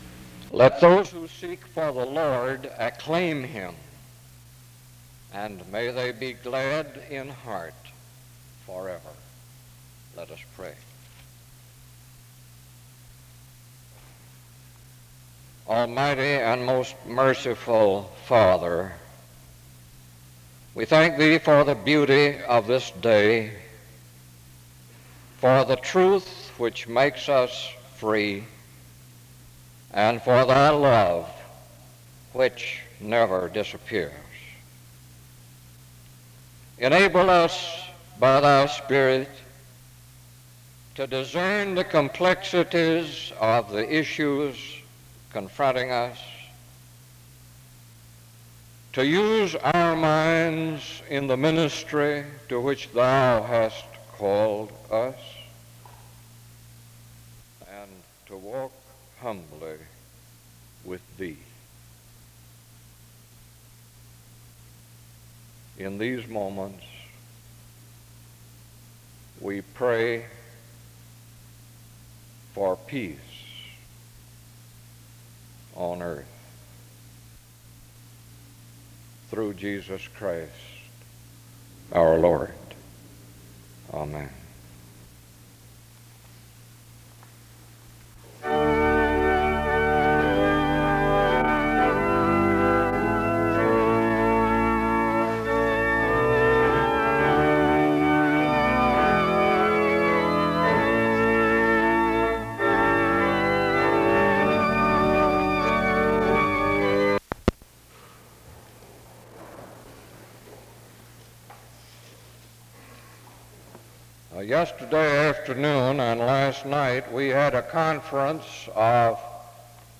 Music plays from 1:27-1:47.